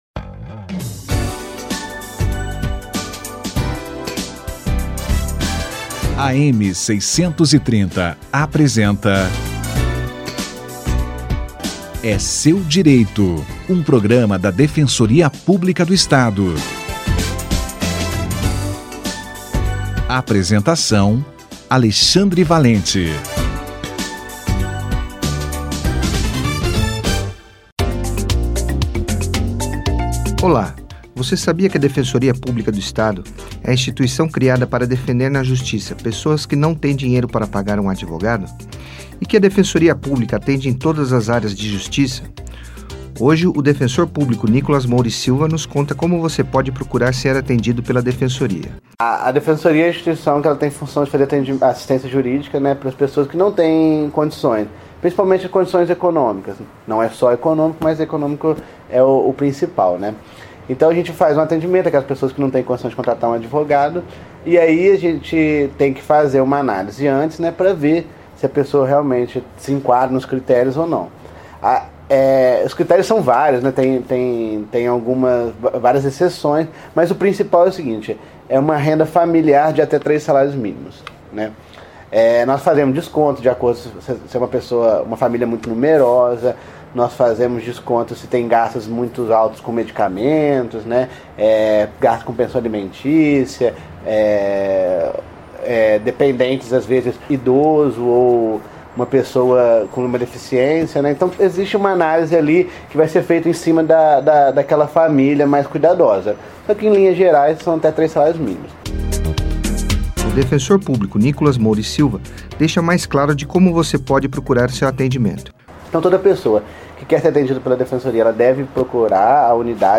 Quem pode buscar atendimento na Defensoria Pública - Entrevista